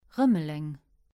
Rumelange (French pronunciation: [ʁymlɑ̃ʒ]; Luxembourgish: Rëmeleng [ˈʀəməleŋ]